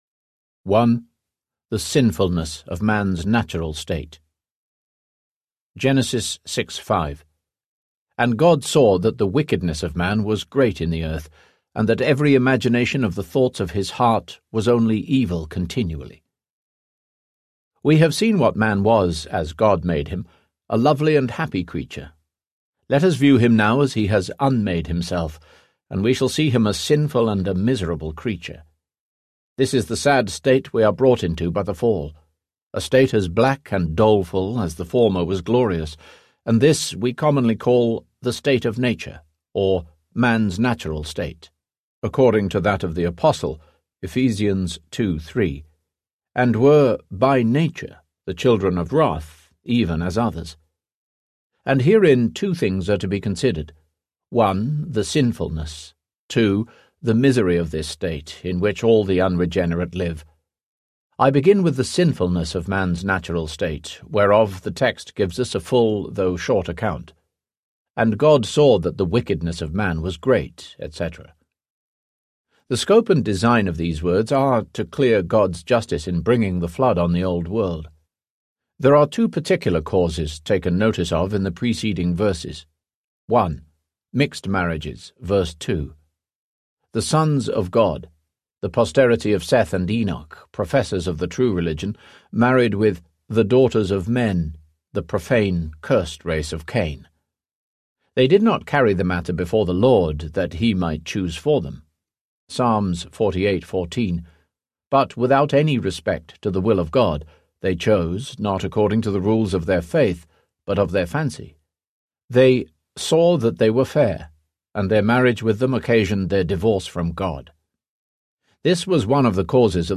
Human Nature in Its Fourfold State Audiobook
20.0 Hrs. – Unabridged